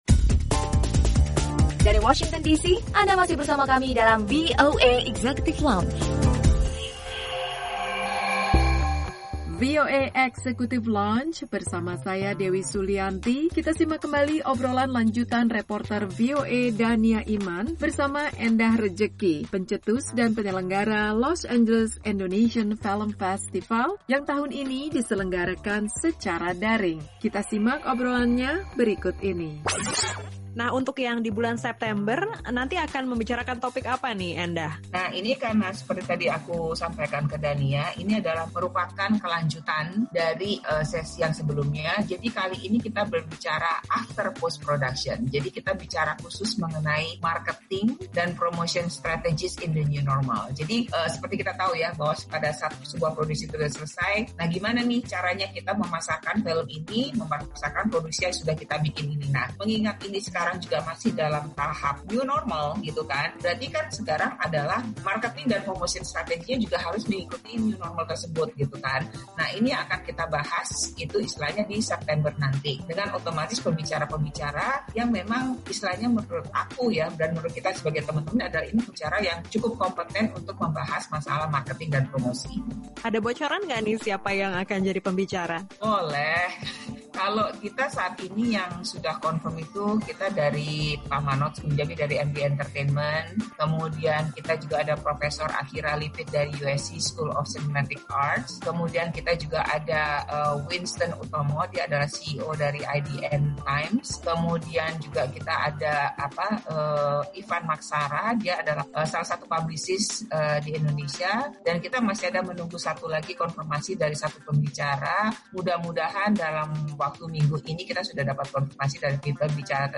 Obrolan lanjutan reporter